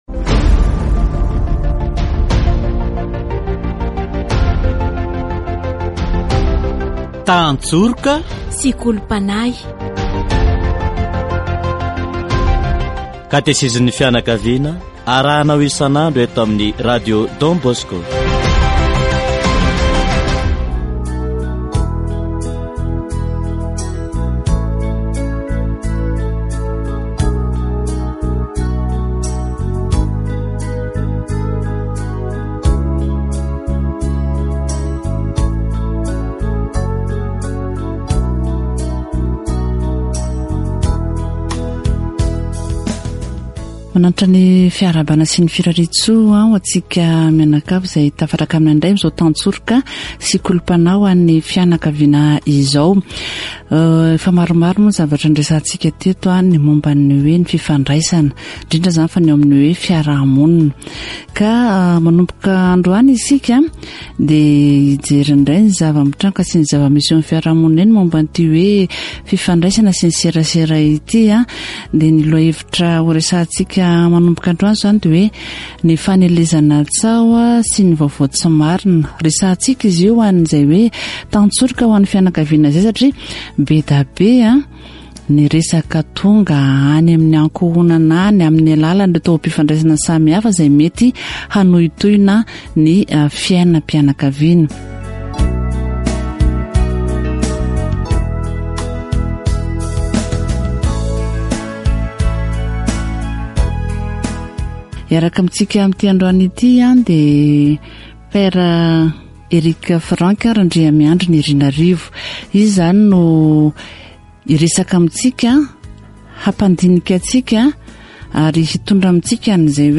The important thing to remember is to make every effort to always tell the truth and not to give in to these rumors. Catechesis on the spread of rumors